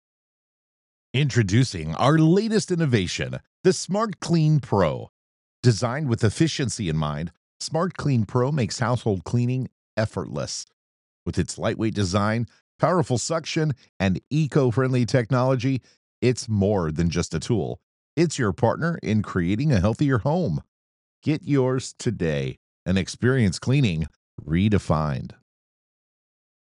:30 Commercial Spot - SmartCleanPro